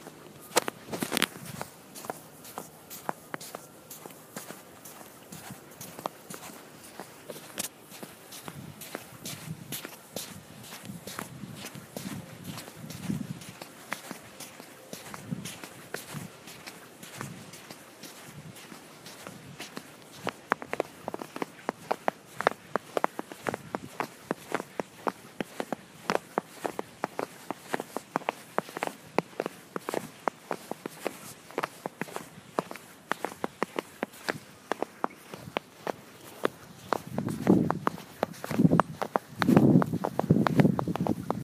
Field Recording 3
Location: Outside of the Spiegel Theater Sounds: Wind, snow crunching, snow slushing